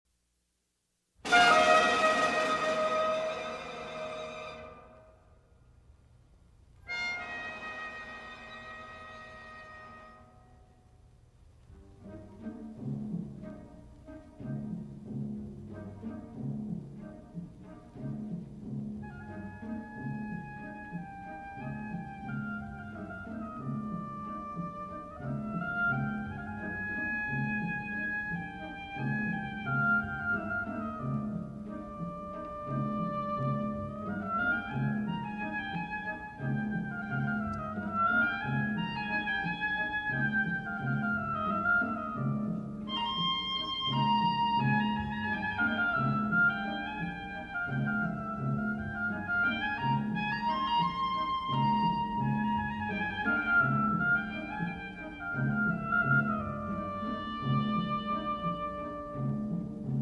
Oboe
Harpsichord
Cello